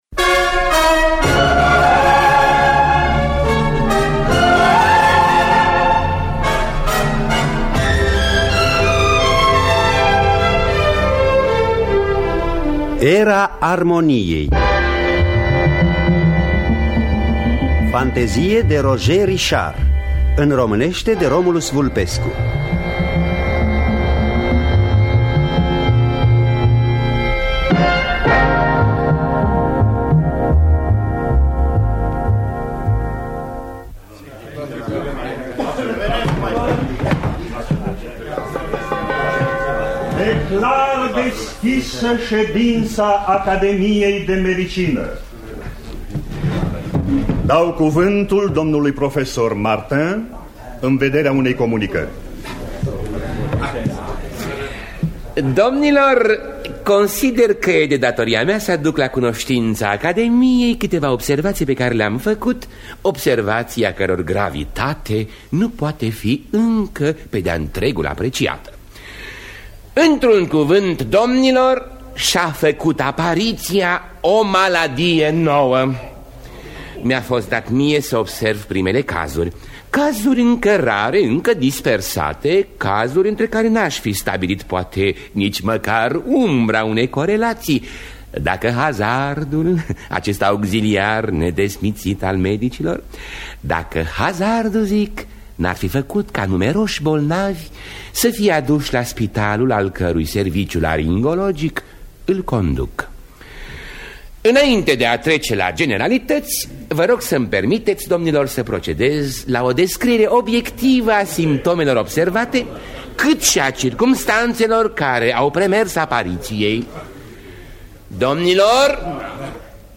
Era armoniei de Roger Richard – Teatru Radiofonic Online